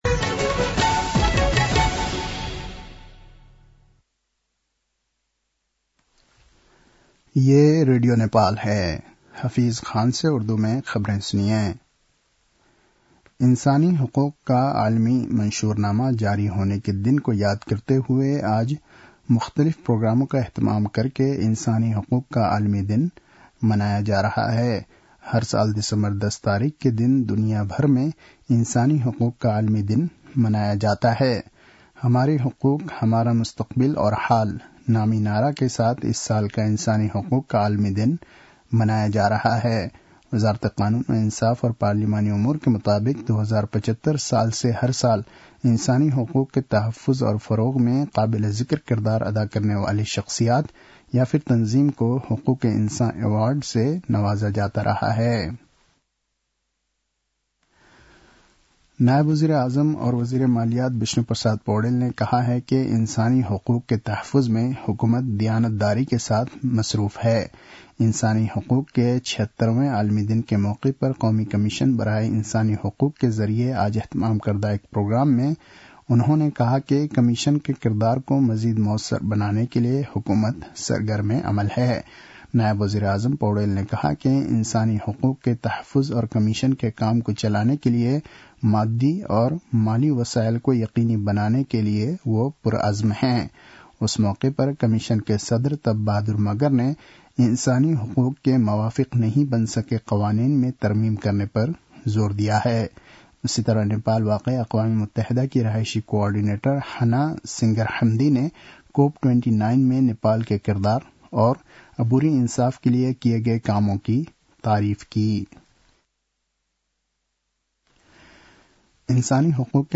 उर्दु भाषामा समाचार : २६ मंसिर , २०८१
Urdu-news-8-25.mp3